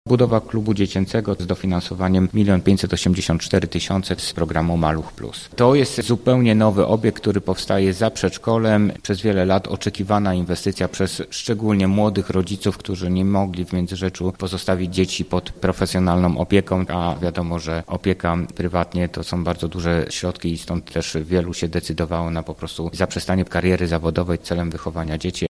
– Chcąc zatrzymać młodych ludzi w naszym mieście musieliśmy wybudować żłobek, bo takie były oczekiwania – mówi Remigiusz Lorenz, burmistrz Międzyrzecza.